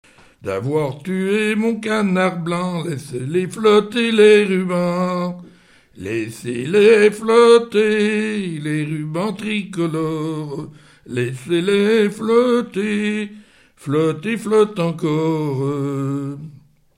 gestuel : à marcher
circonstance : quête calendaire
CHANSON DES CONSCRITS
Genre laisse
Pièce musicale inédite